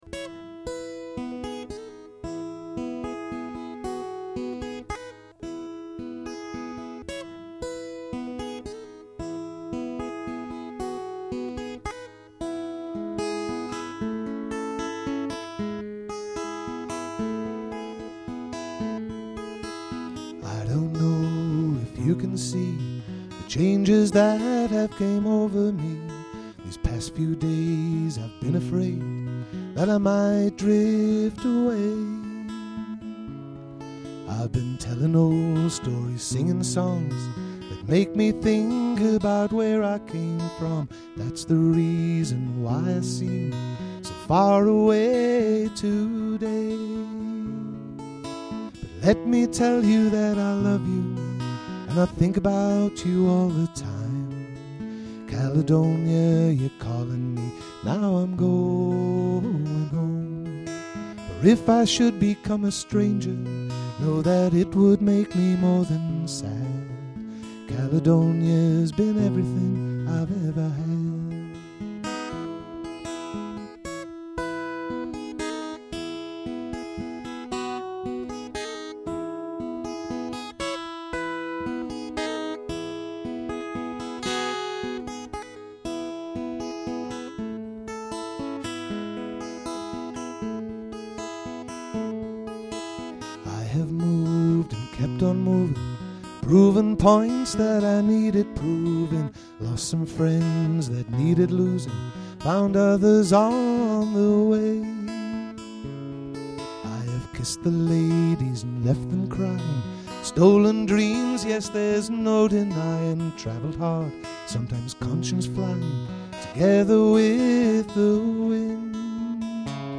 Ashington Folk Club - Spotlight 16 November 2006